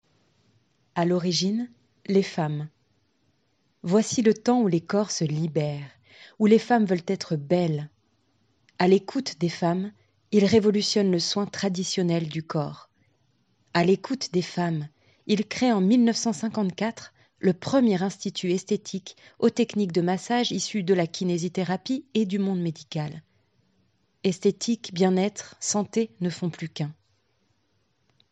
Voix off Démo